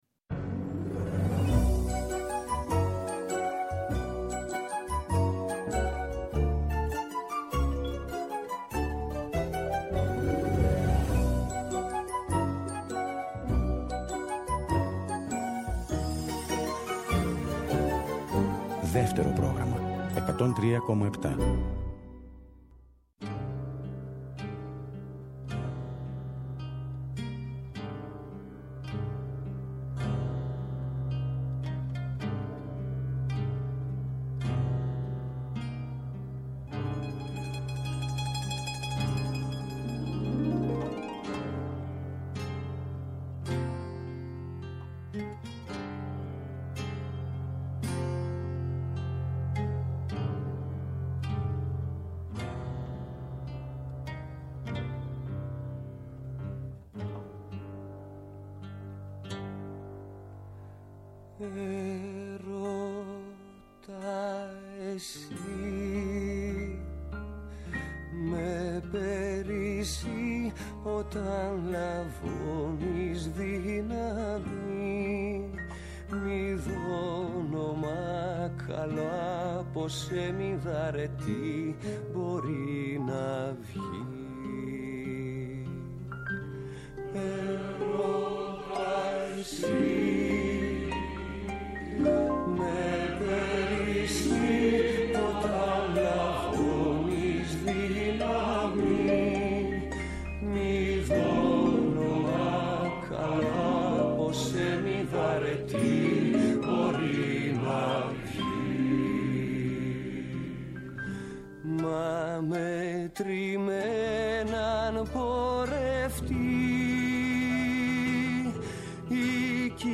ραδιοφωνικό – μουσικό road trip ΔΕΥΤΕΡΟ ΠΡΟΓΡΑΜΜΑ